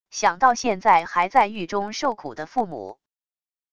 想到现在还在狱中受苦的父母wav音频生成系统WAV Audio Player